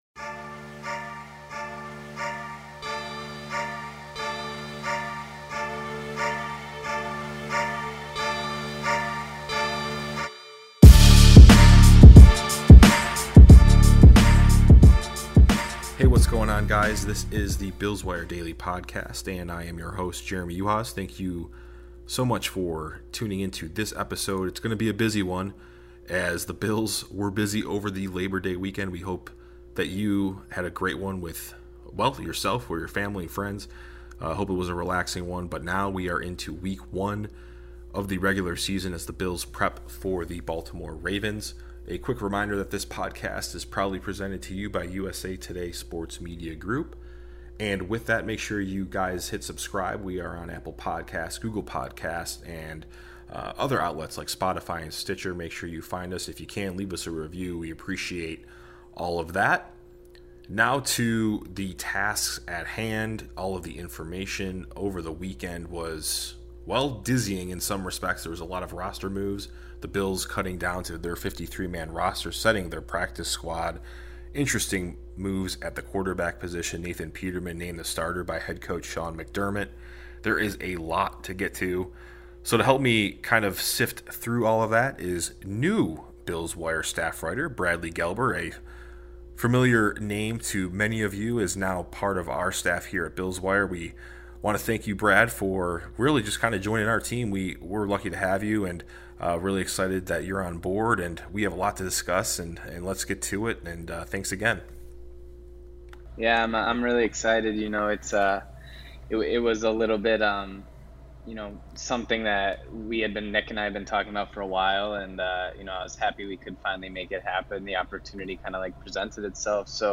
Also the pair discuss final cuts and all of the notable roster moves over the Labor Day weekend.